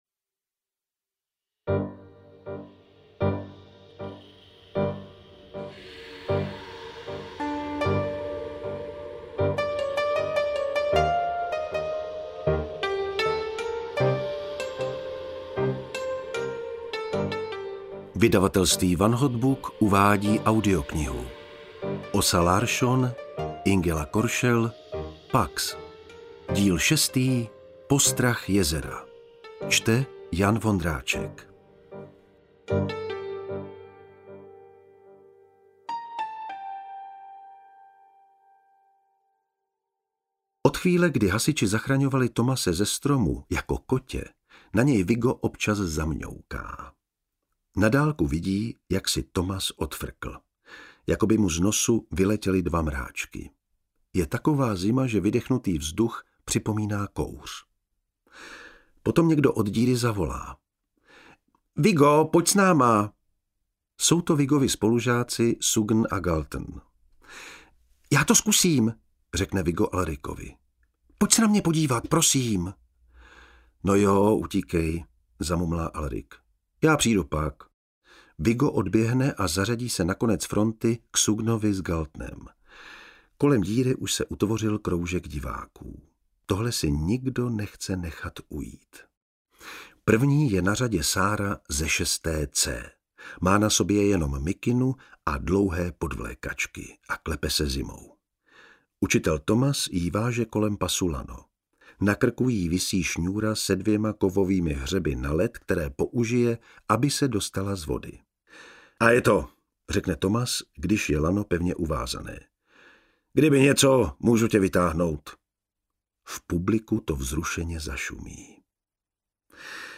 PAX 6: Postrach jezera audiokniha
Ukázka z knihy